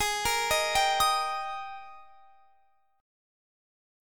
Listen to G#M7sus2 strummed